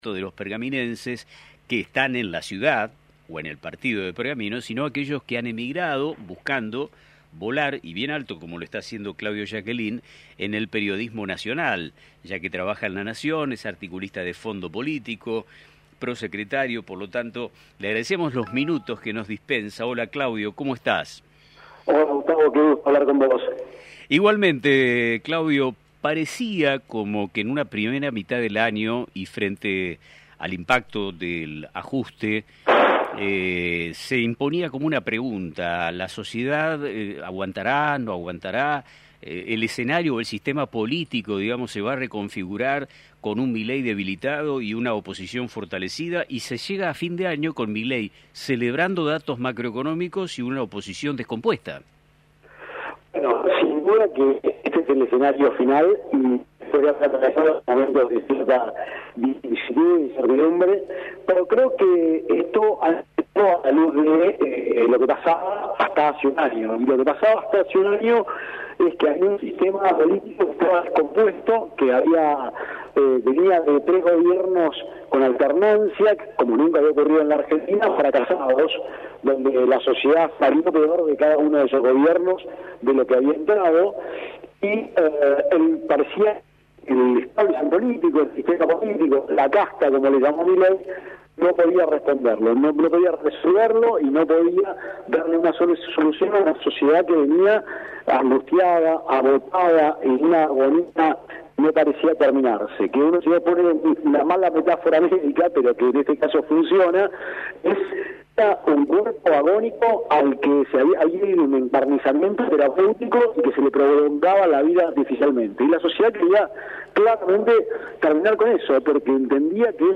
En una profunda entrevista